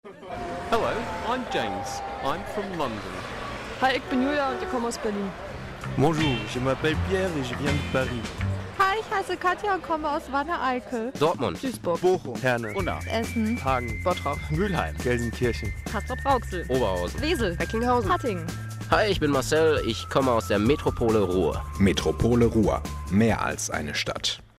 Metropole Ruhr - Mehr als eine Stadt (mp3, 388 KB) Nach meiner Erfahrung besser mit dem RealPlayer abzuspielen, der Mediaplayer gab nur Mono durch.
Dieser Radiospot war ein Teil des Beitrags meiner Gruppe, den wir neben einem Filmstoryboard und der generellen Botschaft produziert haben.